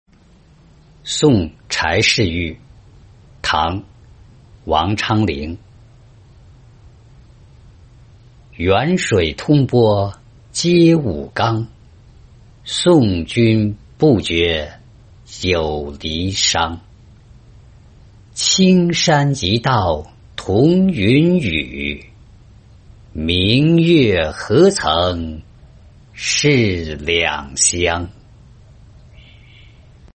送柴侍御-音频朗读